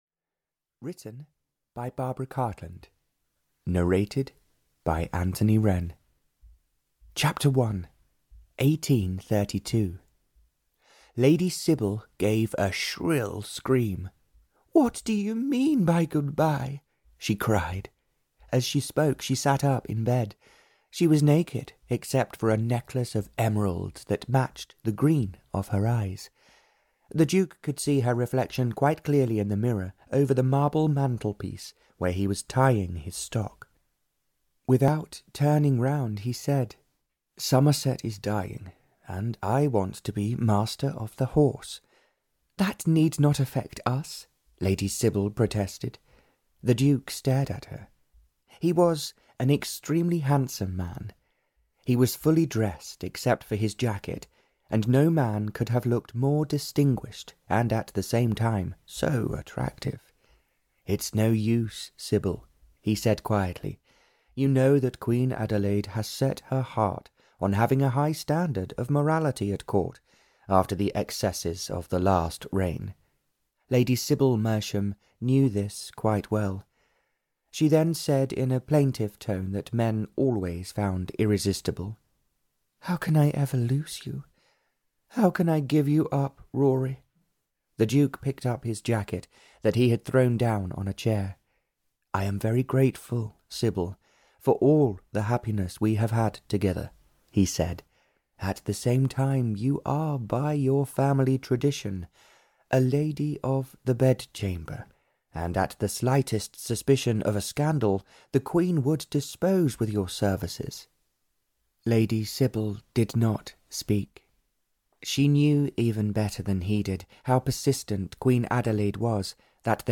The Unbroken Dream (EN) audiokniha
Ukázka z knihy